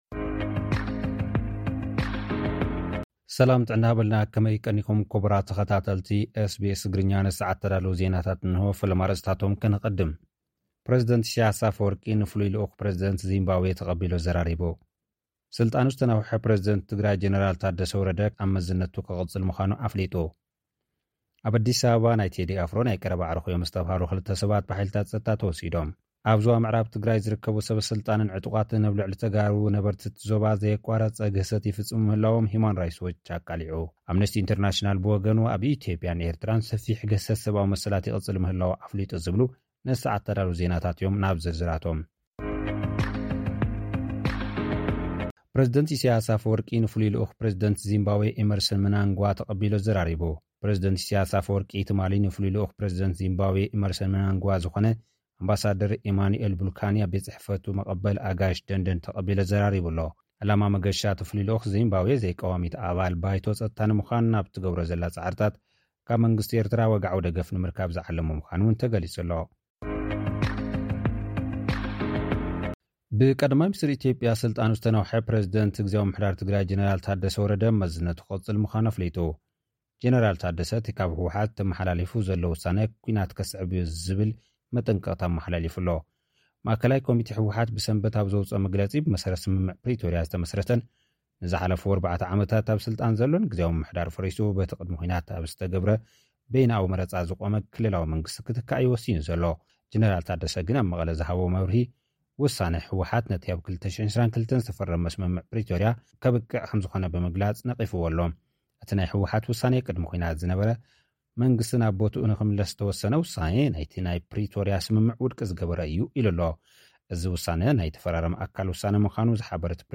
SBS Tigrinya Homeland Report